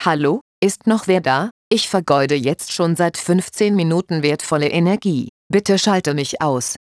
hier mal eine mögliche Sprachmeldung für die neue Sonderfunktionalität "Inaktivitätsalarm" 5 / 10 / 15 Minuten oder ohne Angabe von Zeit